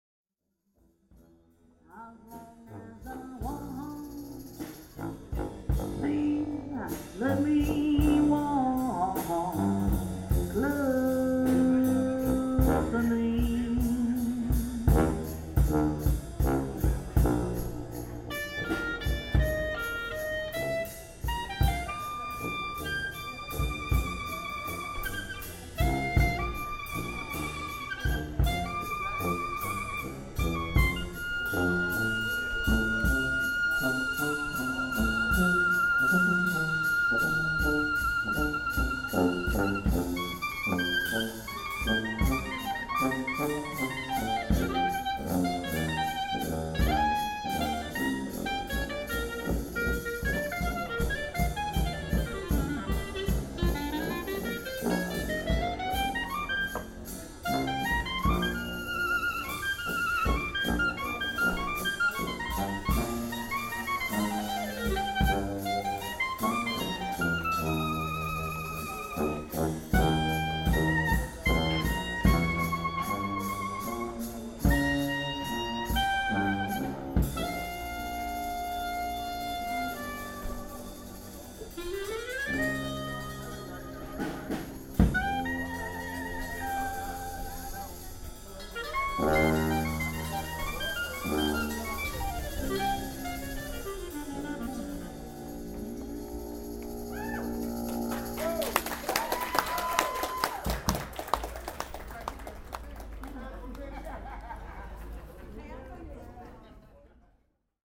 She sang and then went into what seemed like effortlessly soaring clarinet – listen to it here.
new orleans busker
new-orleans-busker.mp3